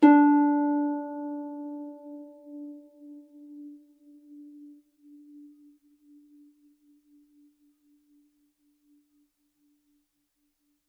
KSHarp_D4_mf.wav